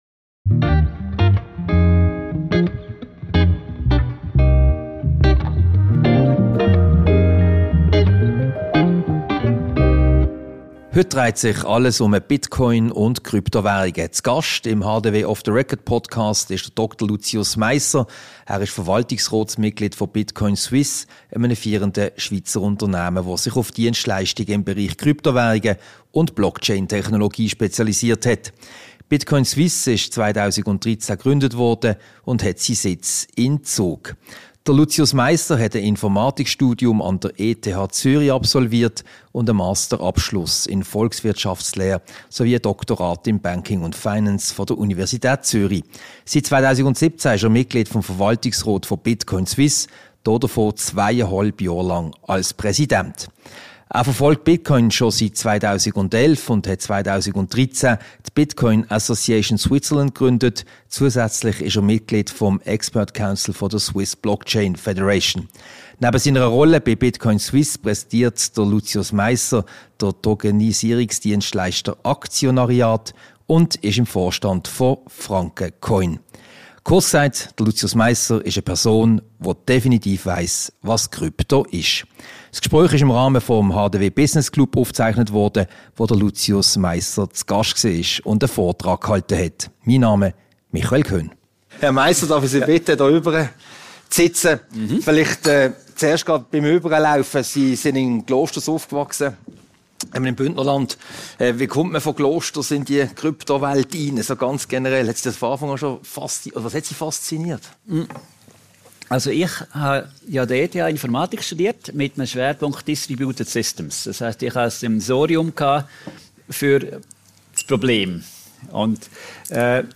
Ein Gespräch über die Kryptowährungen im Allgemeinen und Bitcoin im speziellen.
Diese Podcast-Ausgabe wurde anlässlich des HDW Business Club Lunchs vom aufgezeichnet.